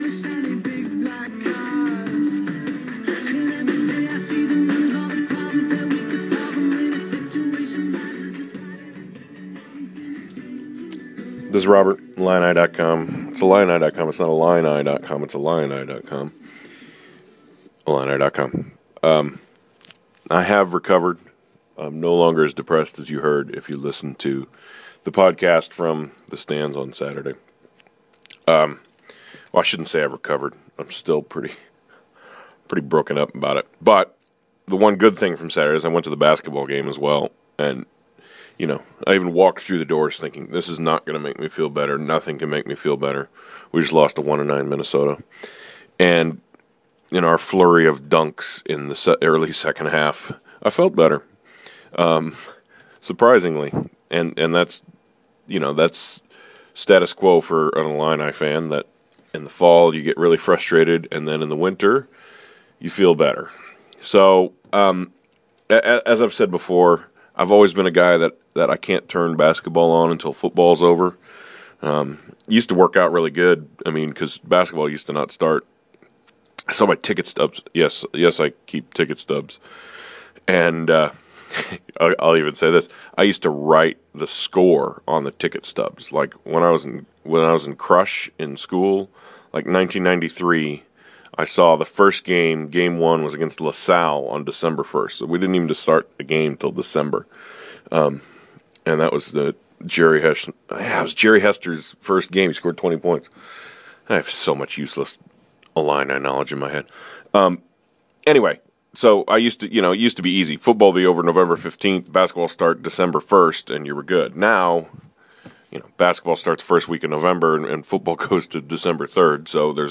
From the back porch, 5 minutes after the game ended